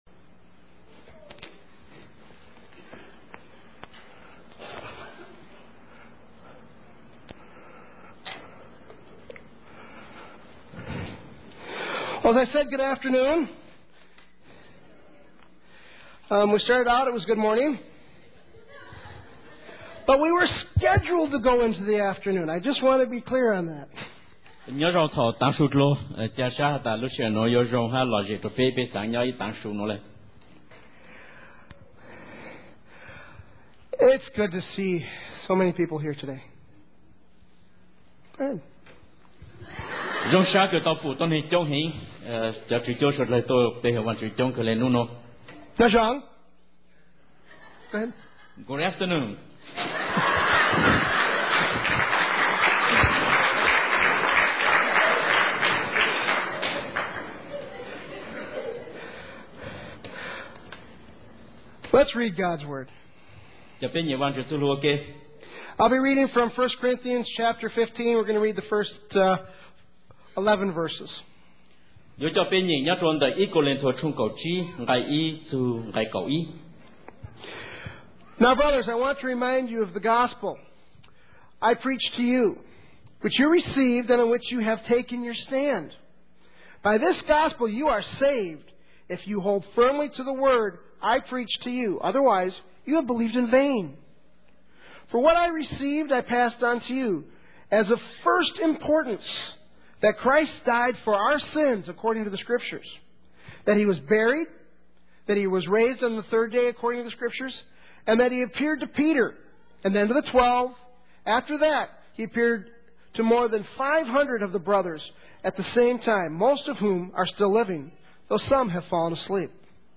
Joint Thanksgiving service